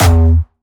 Jumpstyle Kick 4
12 E2.wav